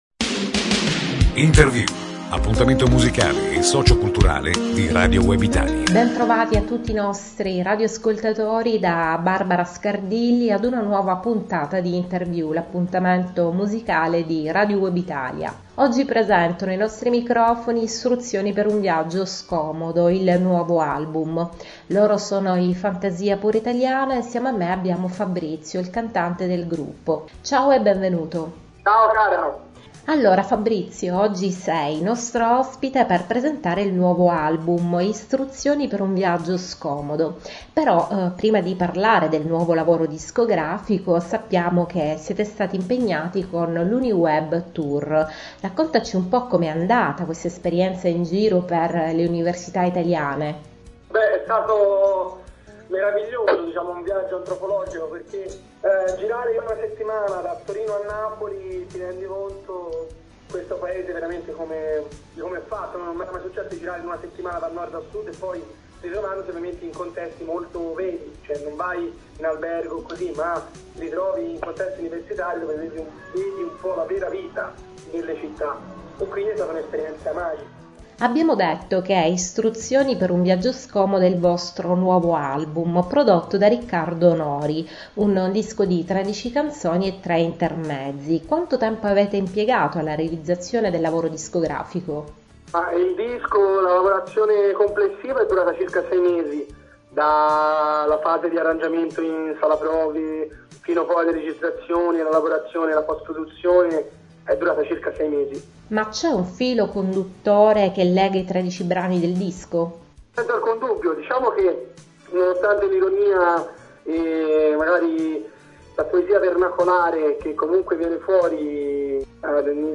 Fantasia Pura Italiana: l’intervista per il nuovo album “Istruzioni per un viaggio scomodo”